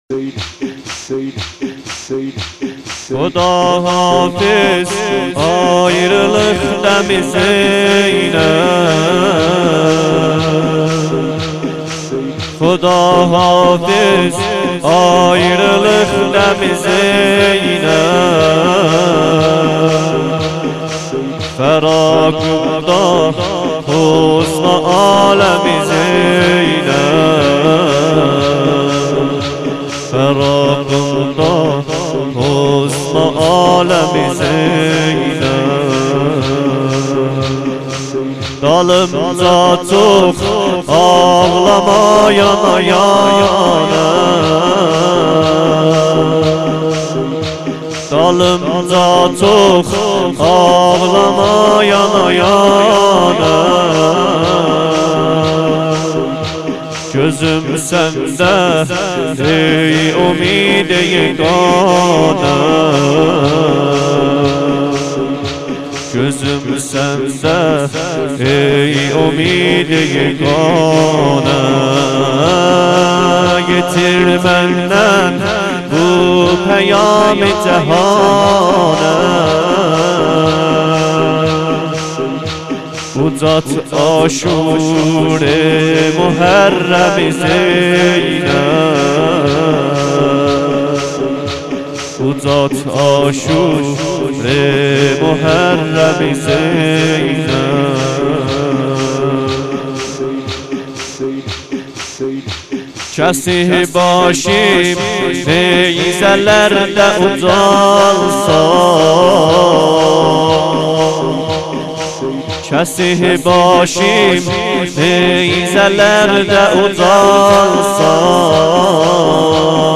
دانلود نوحه ترکی بسیار زیبای خداحافظ ایریلیق دمی زینب